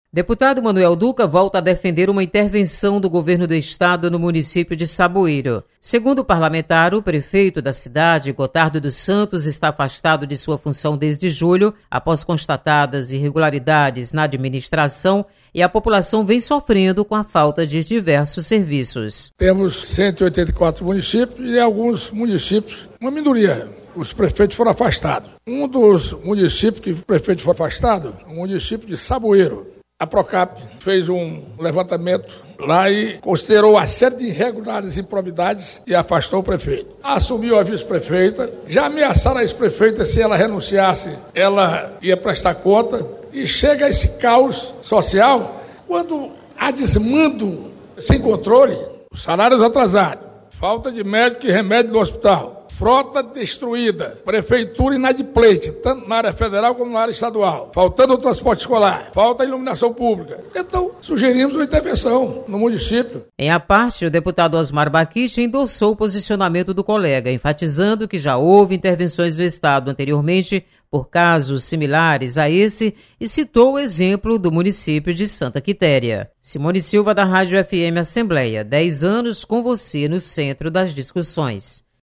Deputado Manoel Duca cobra intervenção estadual em Saboeiro. Repórter